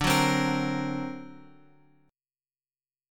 D+7 chord {x 5 4 5 x 6} chord
D-Augmented 7th-D-x,5,4,5,x,6.m4a